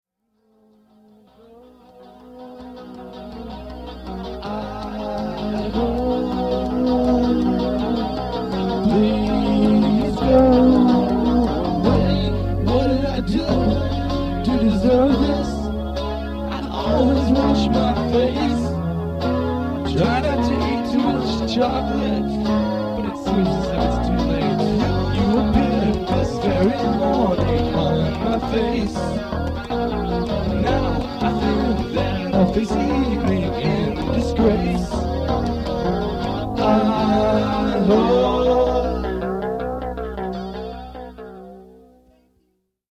this was my first full-length compilation. between '86 and '89 i'd been collecting songs recorded in various locations. but with the advent of my own 4-track, i was able to work alone and this was the result. many tracks are instrumental and, again, very rough.